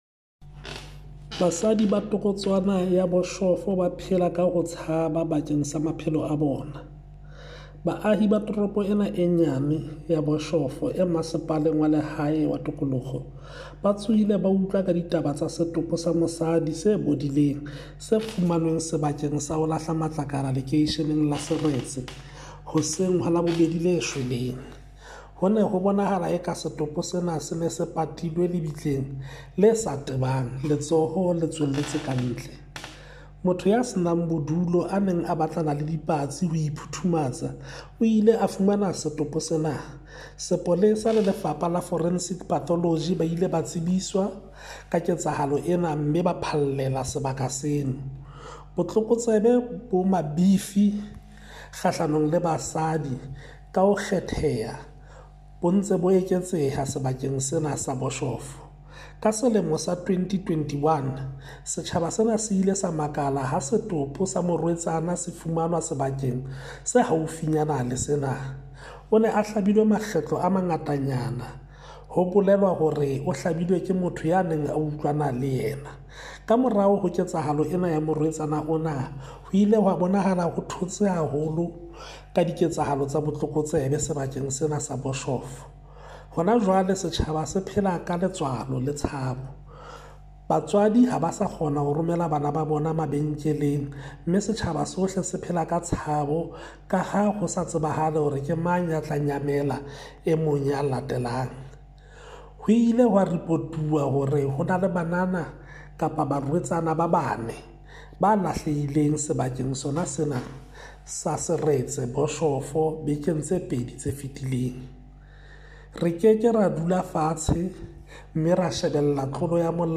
Sesotho soundbites by Cllr Hismajesty Maqhubu.
Sotho-voice-4.mp3